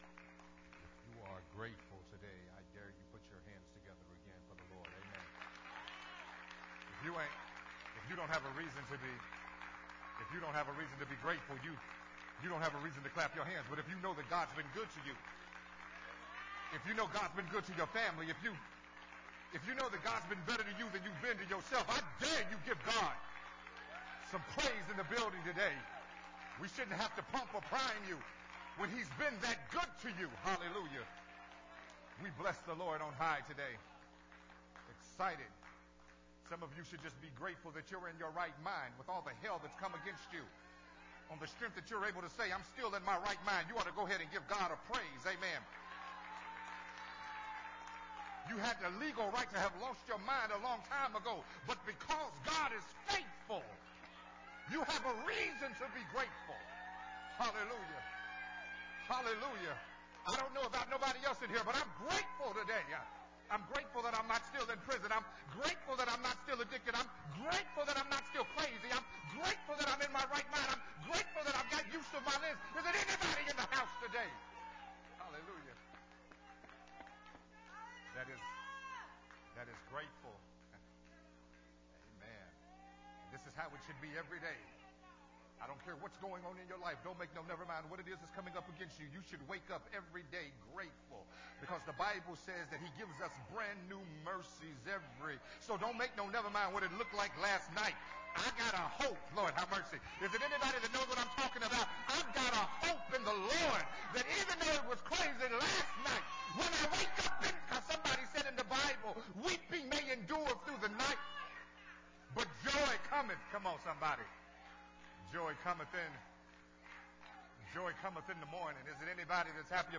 Part 9 of the sermon series